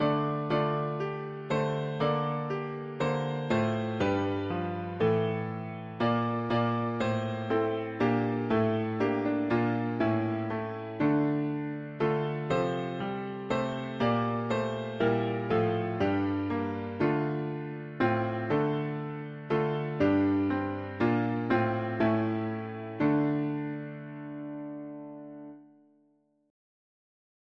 For … english christian 4part
Music: English Traditional, 15th c.
2017 Key: D minor Meter